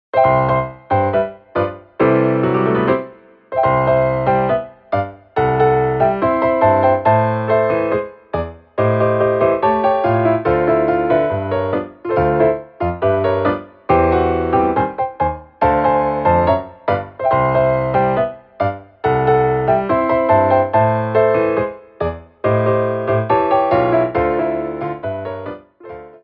TANGO
8x8 (Fast)